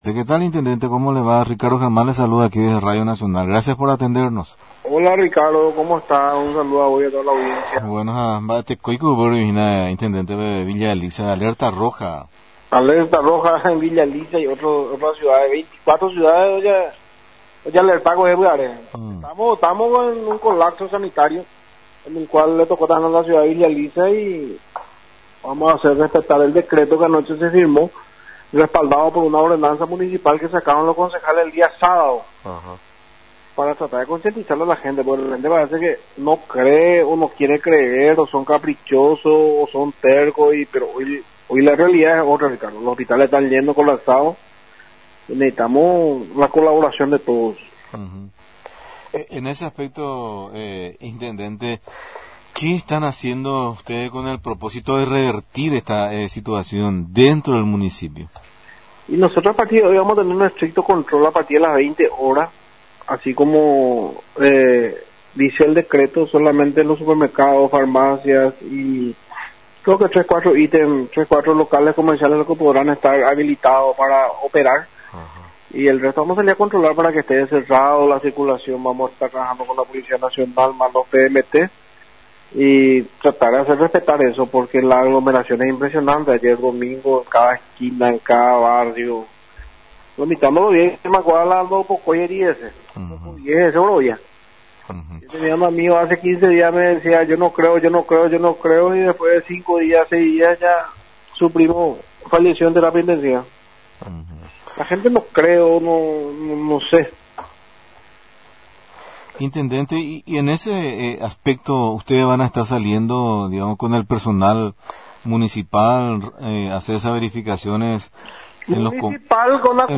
Ricardo Estigarribia, Intendente Municipal de la Ciudad de Villa Elisa, manifestó que la Ciudad no está ajena a problemática de la pandemia y la alerta roja por Covid-19, por lo que van hacer respetar a rajatabla el Decreto Presidencial, respaldado por una ordenanza.